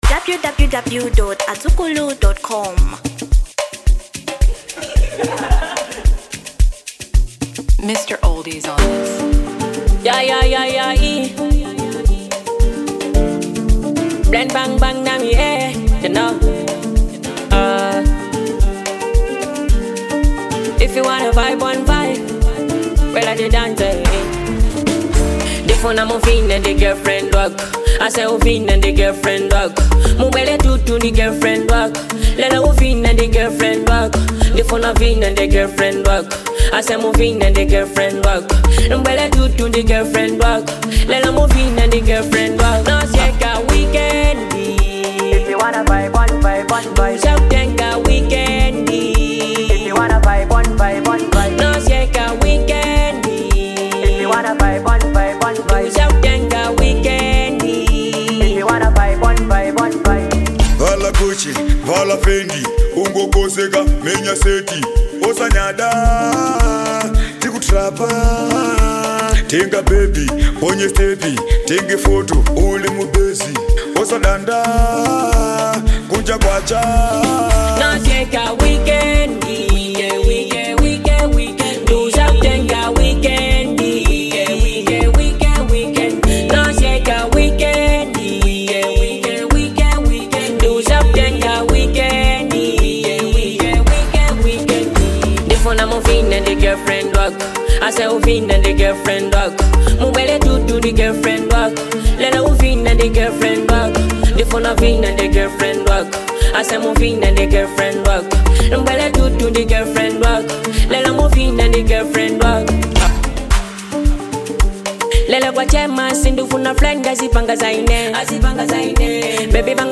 Genre Reggae & Dancehall